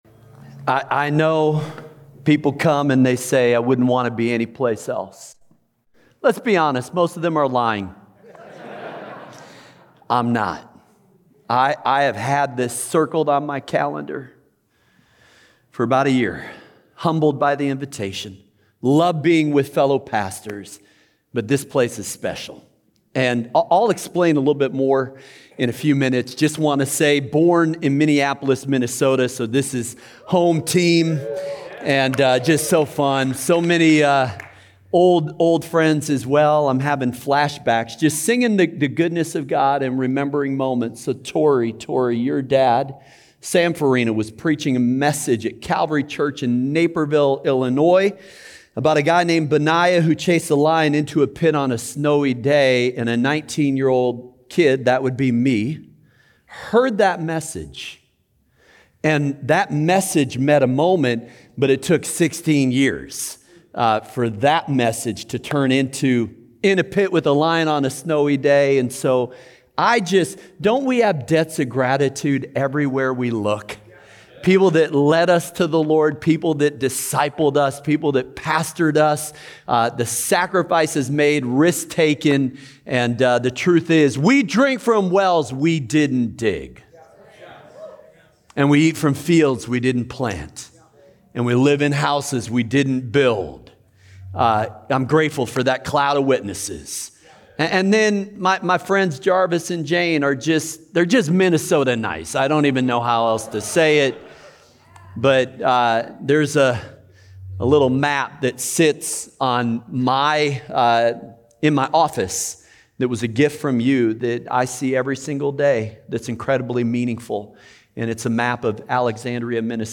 Message by Mark Batterson on Tuesday, Sept. 30 at 6:30 pm at Prayer & Fasting Retreat